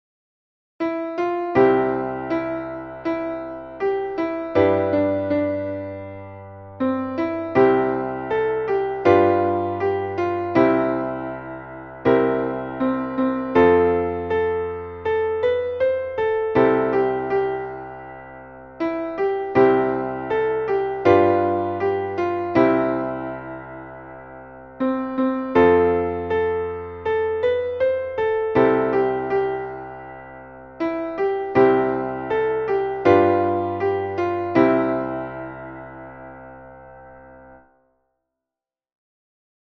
Traditionelles Volkslied um 1750